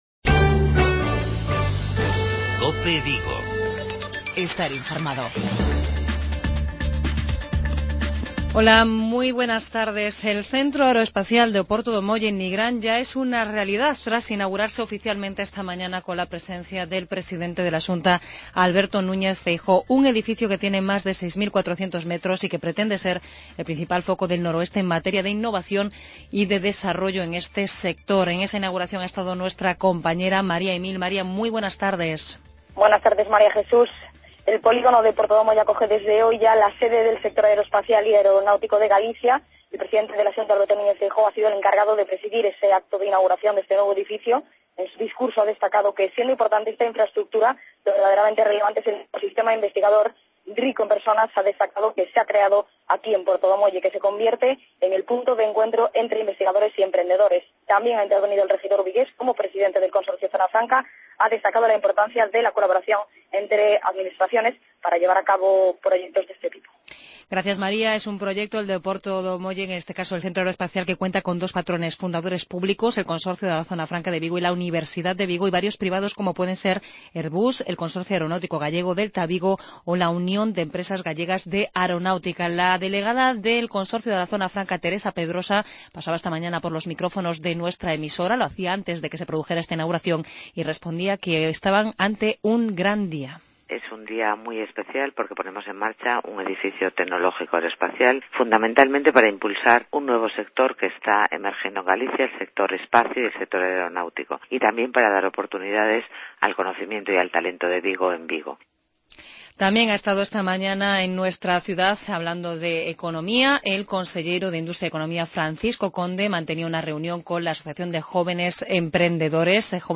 Informativos Vigo